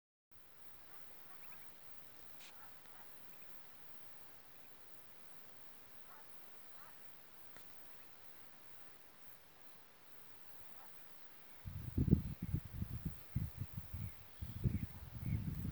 Upland Sandpiper (Bartramia longicauda)
Location or protected area: Laguna El Guanaco
Condition: Wild
Certainty: Recorded vocal